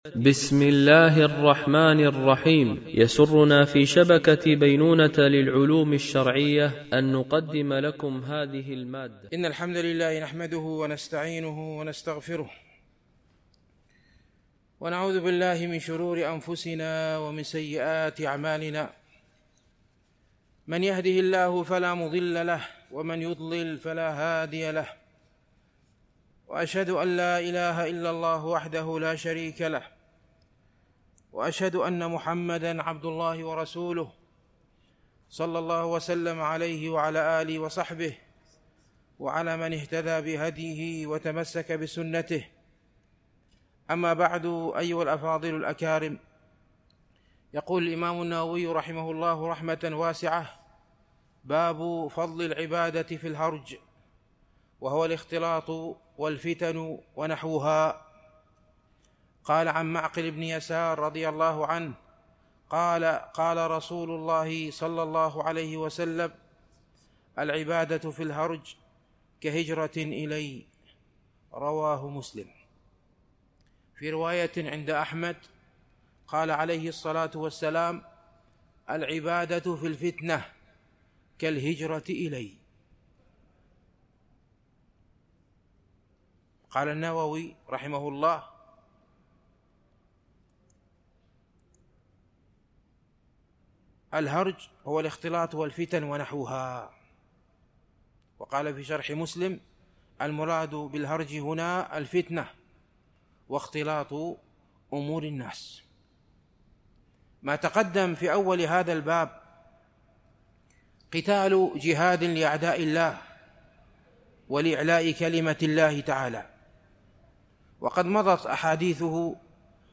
شرح رياض الصالحين – الدرس 354 ( كتاب الجهاد - الحديث 1374 )